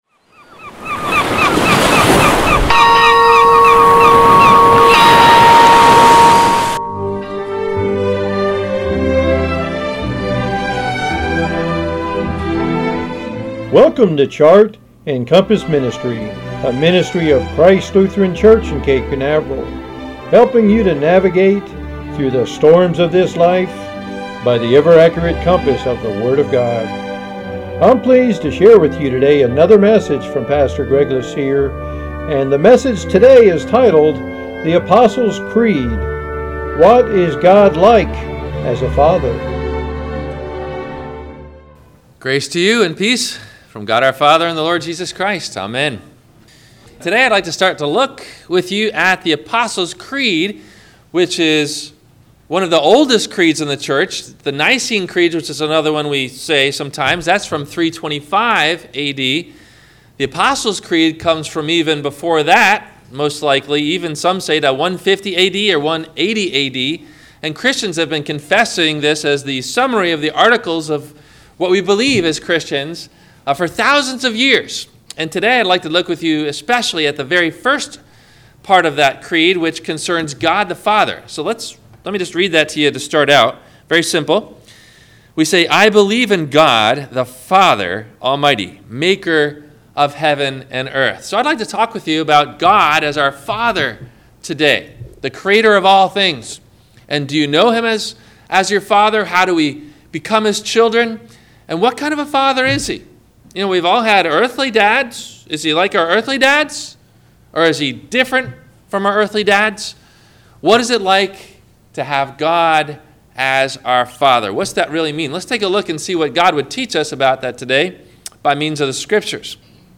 The Apostles Creed – What is God Like as a Father? – WMIE Radio Sermon – July 03 2017